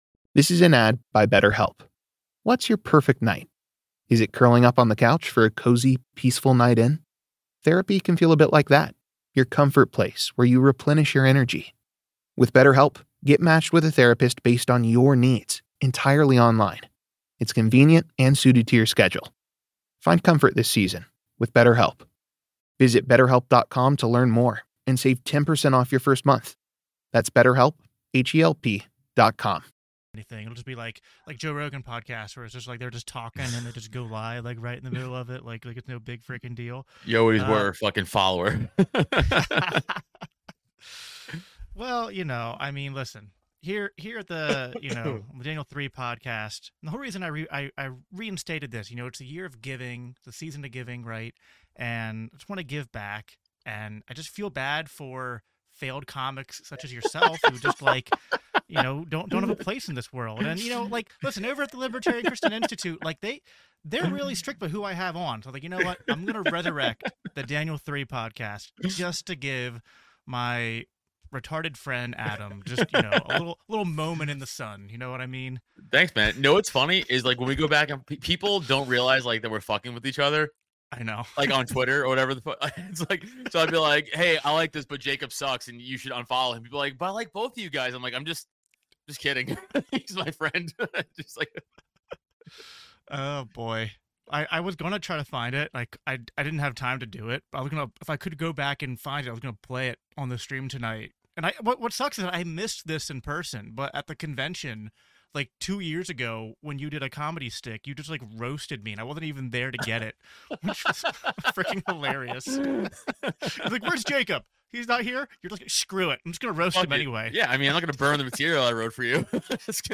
Daniel 3 Ep 95: Health, Humor, and Escaping the Circus: A Conversation